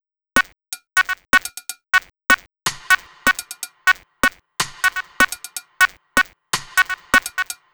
Bleep Hop Vox Loop.wav